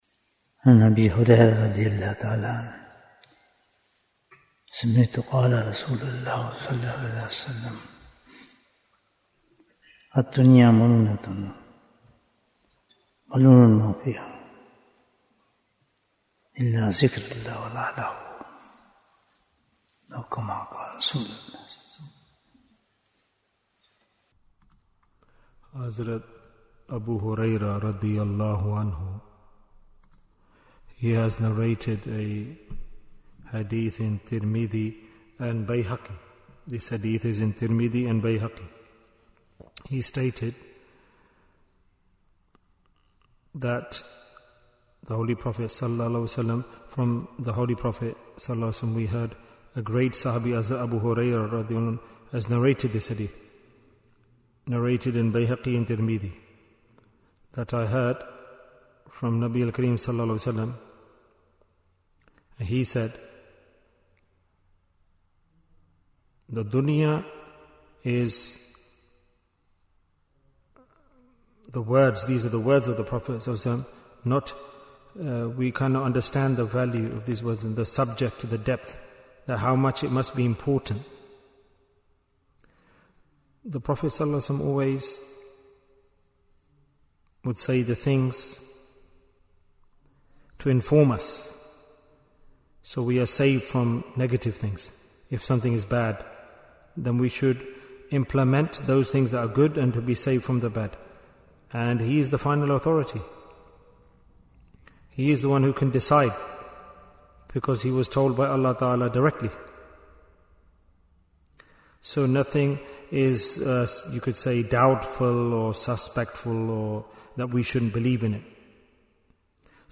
Jewels of Ramadhan 2026 - Episode 16 Bayan, 38 minutes25th February, 2026